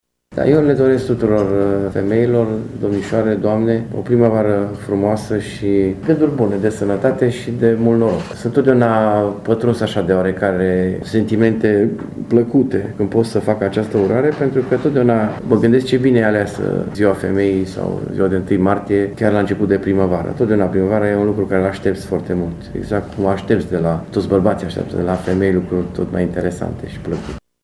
Primarul Dorin Florea a transmis şi un mesaj tuturor femeilor cu prilejul zilei de 8 Martie: